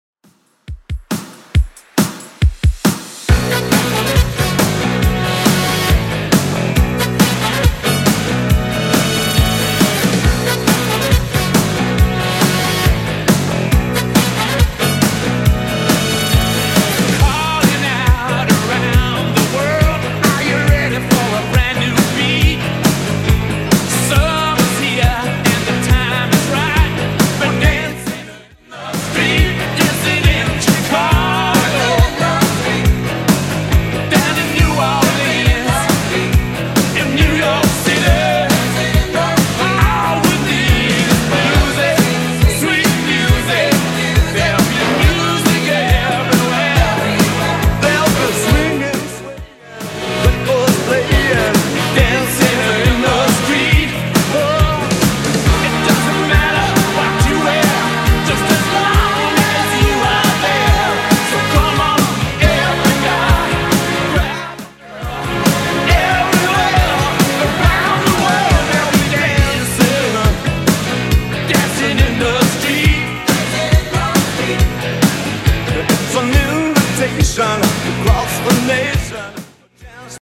BPM: 138 Time